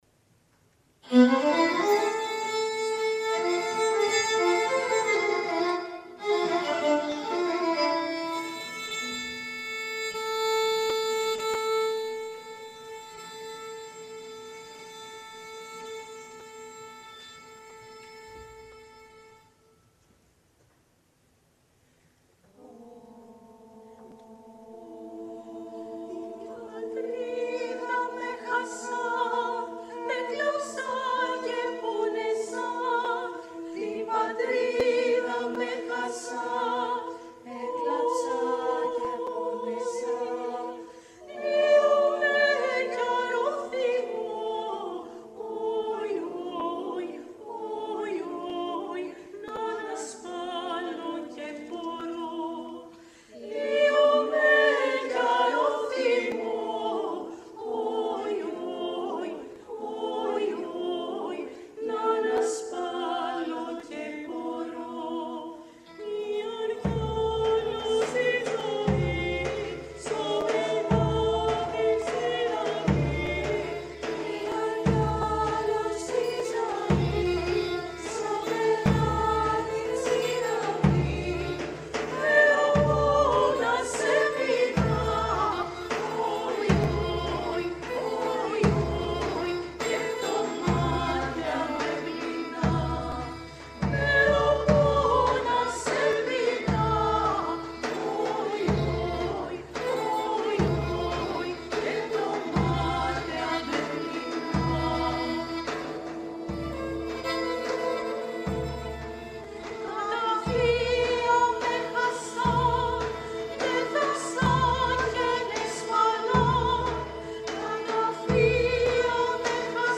Α. Μπαλτά – 2 Ποντιακά τραγούδια γυναικεία χορωδία και παραδοσιακά κρουστά (live)